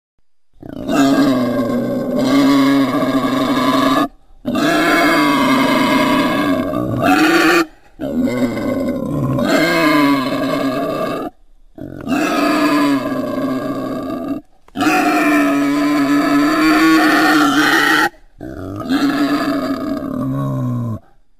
Звук рычащего бородавочника